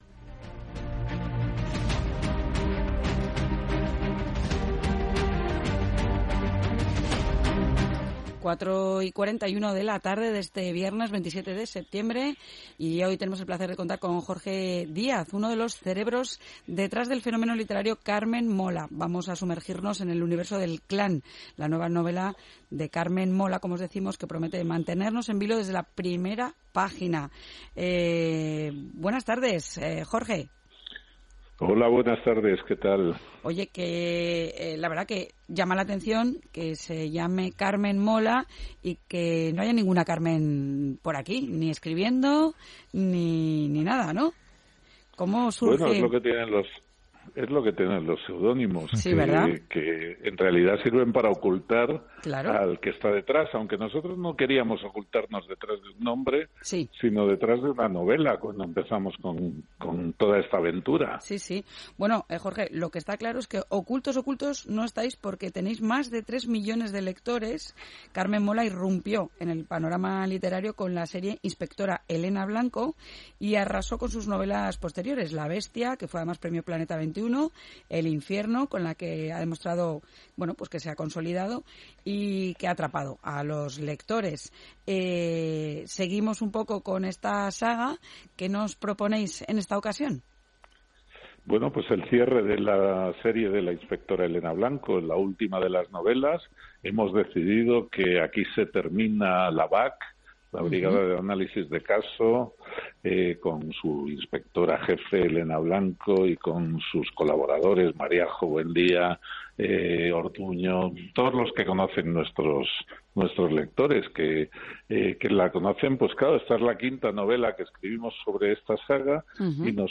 hemos hablado con Jorge Díaz, uno de los cerebros detrás del fenómeno literario Carmen Mola. Entramos en el universo de "El Clan", la nueva novela de Carmen Mola que promete mantenernos en vilo desde la primera página. Se trata de el brutal desenlace de la serie "Inspectora Elena Blanco" y en esta nueva entrega Elena Blanco y la BAC se enfrentan a un enemigo poderoso y secreto: El Clan.